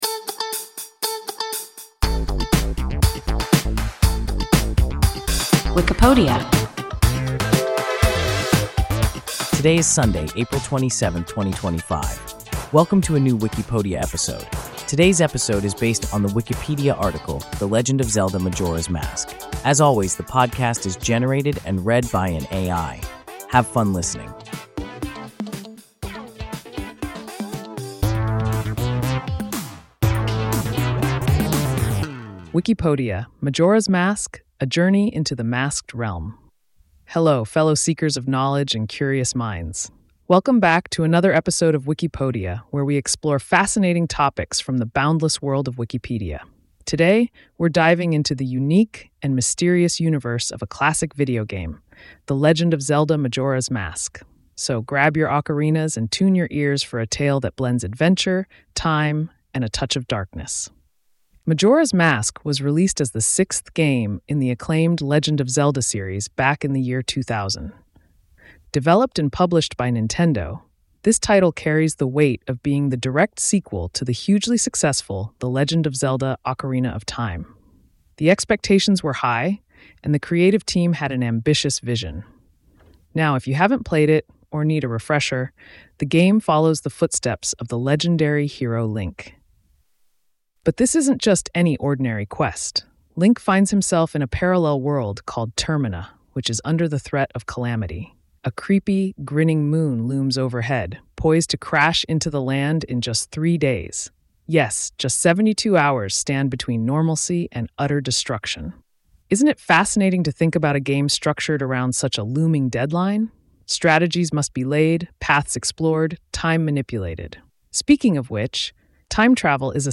The Legend of Zelda: Majora’s Mask – WIKIPODIA – ein KI Podcast